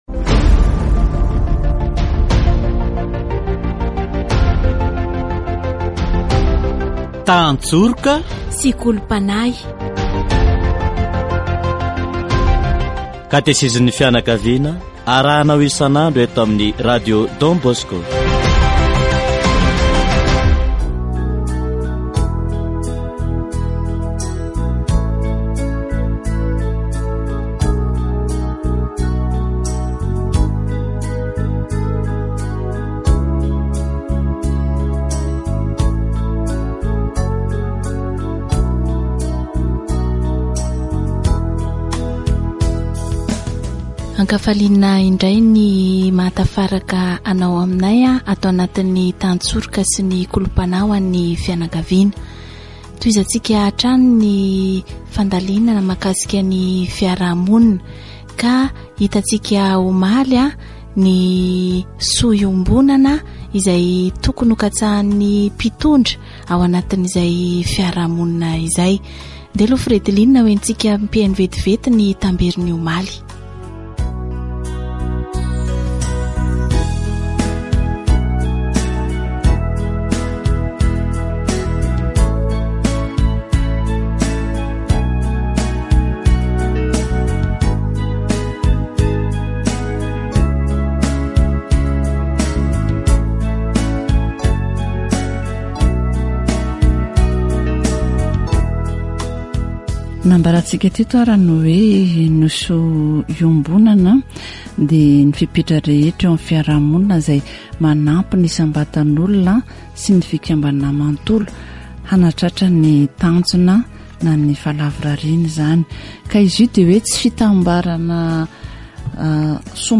Responsabilités : l'engagement pour la paix, l'organisation de l'autorité publique, la préservation de l'environnement. Catéchèse sur La société selon le dessein de Dieu